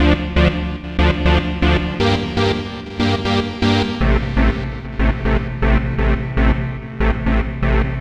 Session 14 - Synth Bass Melody.wav